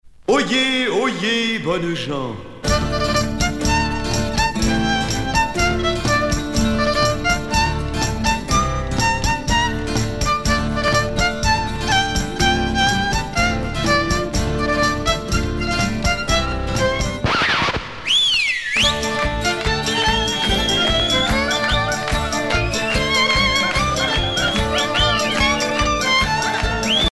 Couplets à danser